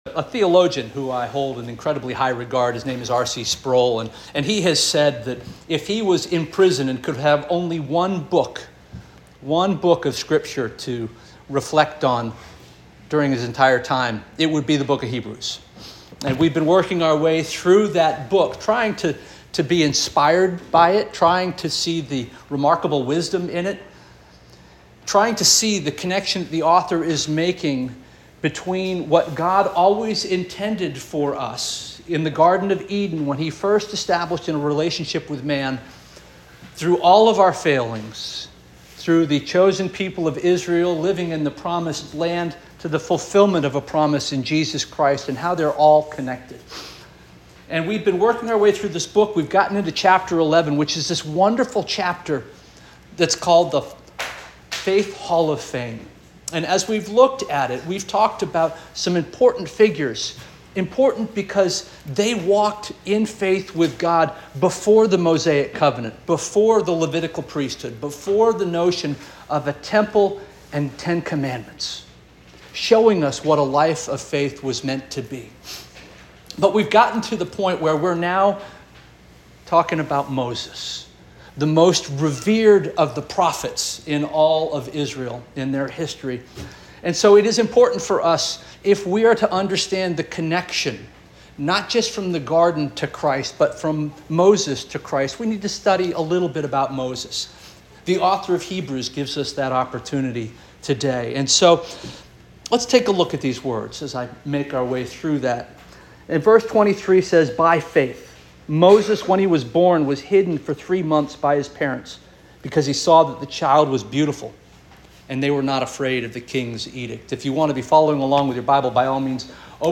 May 26 2024 Sermon - First Union African Baptist Church